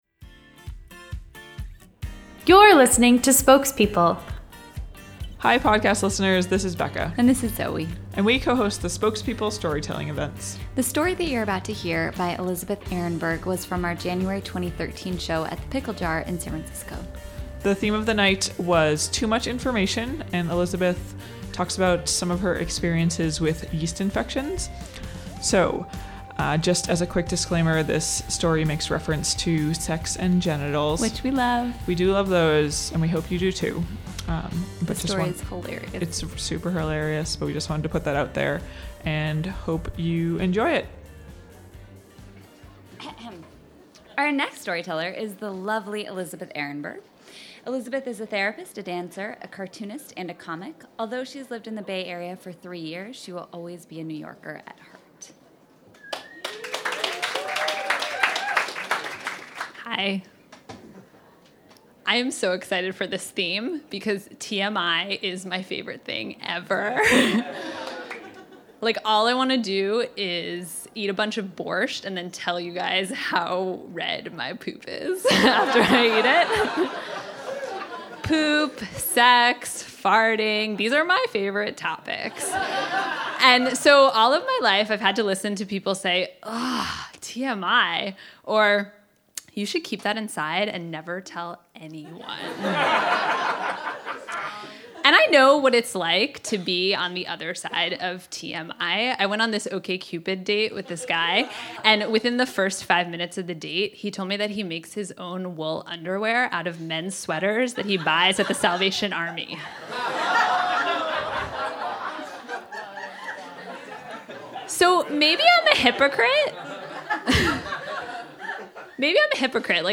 Or don’t, and you too may find yourself up late one night with a tray of ice cubes and a strategically placed mini fan. This story of a yeast infection à la française comes from our January 2013 show, Too Much Information.